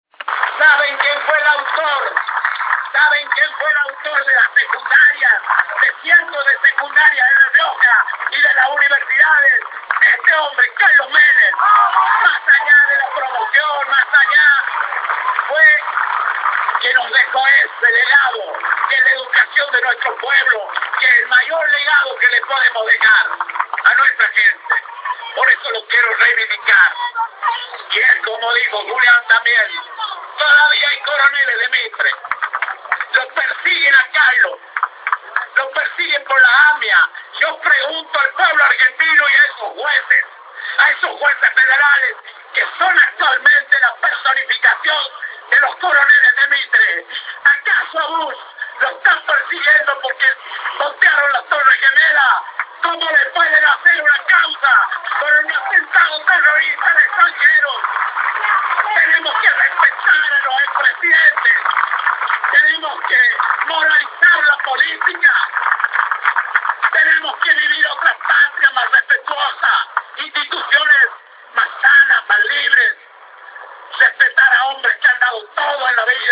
El gobernador Luis Beder Herrera reivindicó este martes al ex presidente Carlos Menem, a raíz de la causa por el atentado contra la mutual de la AMIA. En ese sentido, el mandatario provincial consideró a los jueves como “los coroneles de Mitre” y bregó por el respeto hacia los ex presidentes durante un discurso brindado en el acto homenaje al caudillo Ángel Vicente “El Chacho” Peñaloza.